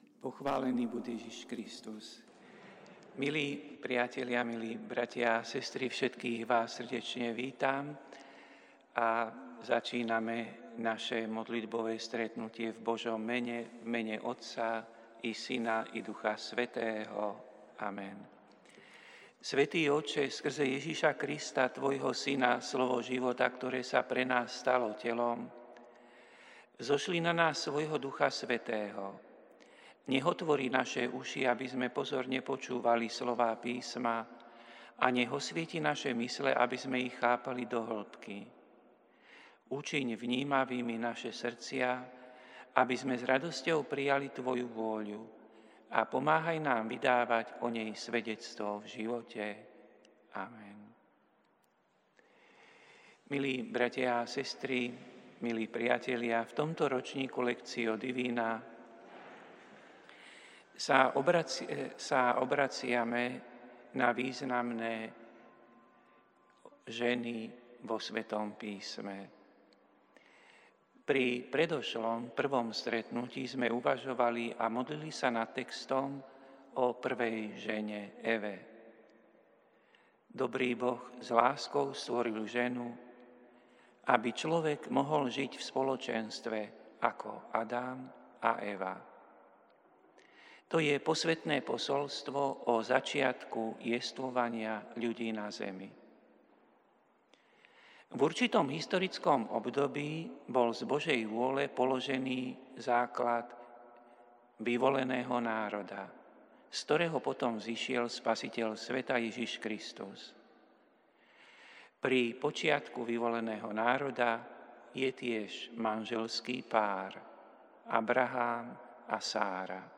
Prinášame plný text a audio záznam z Lectio divina, ktoré odznelo v Katedrále sv. Martina 8. októbra 2025.